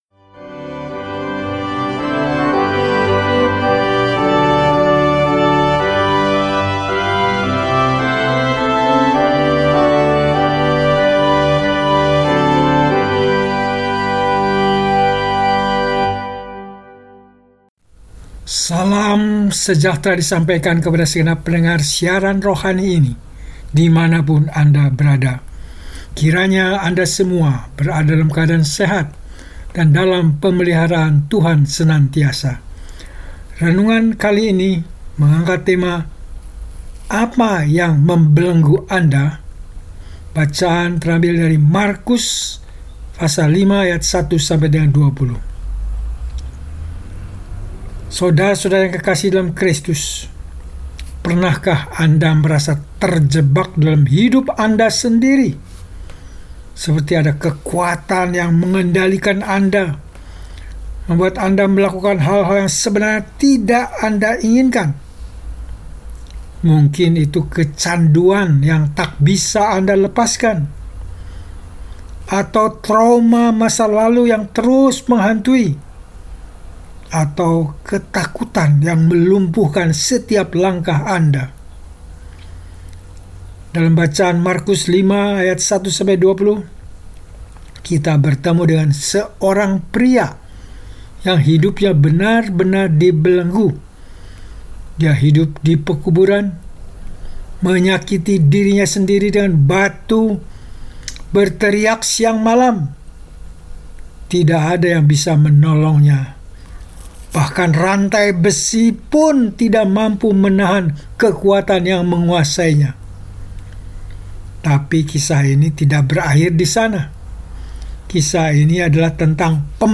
Mimbar dengan renungan singkat untuk memperkaya pemahaman iman Kristen, memantapkan iman, dan lebih lagi makin mencintai Tuhan kita Yesus Kristus.